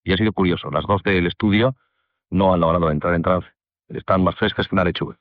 Final de l'experiència i informació des dels estudis de Madrid i Sevilla de com ha anat l'experiència hipnòtica per ràdio.
Informatiu